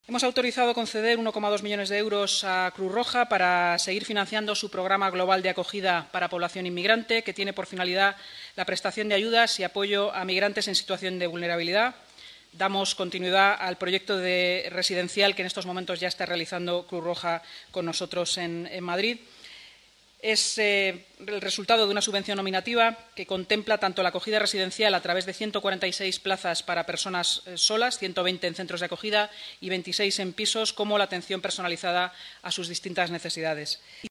Nueva ventana:Así lo ha explicado la portavoz municipal Inmaculada Sanz, en rueda de prensa: